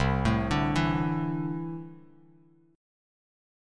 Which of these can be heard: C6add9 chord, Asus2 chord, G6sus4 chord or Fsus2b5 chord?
C6add9 chord